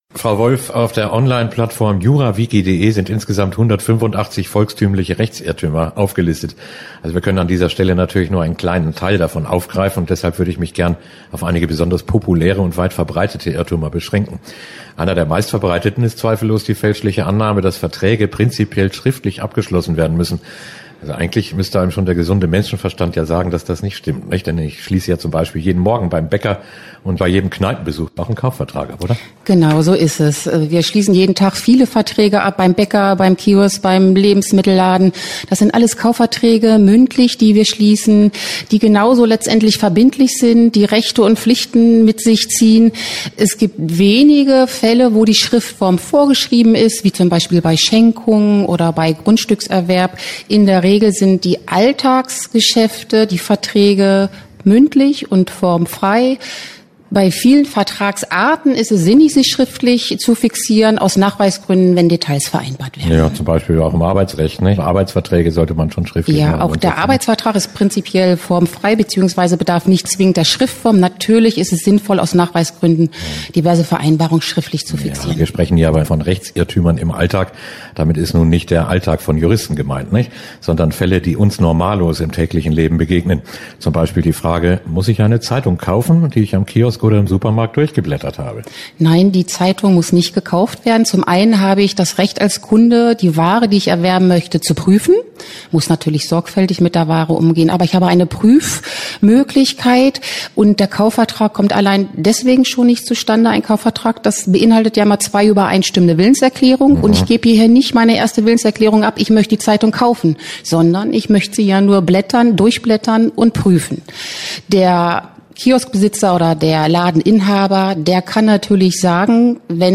Interview-Rechtsirrtuemer_pmm.mp3